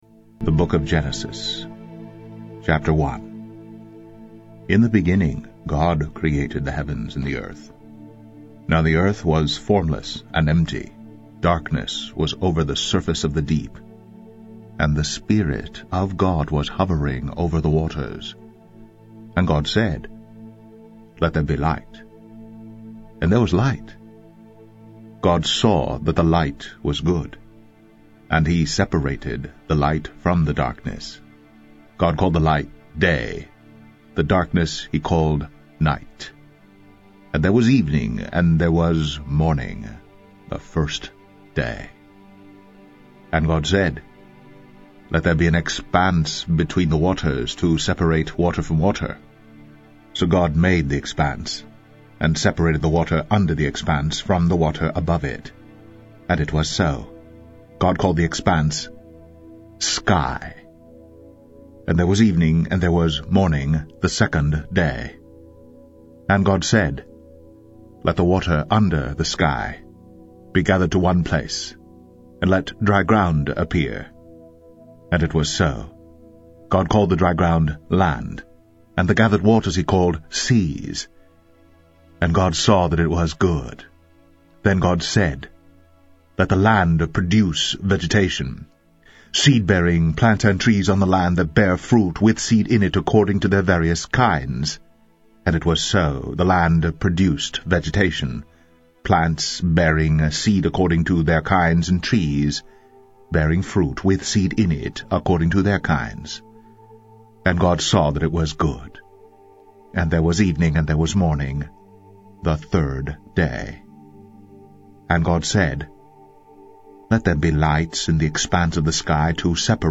Tags: Christian Books Audio books Christian Audio books Media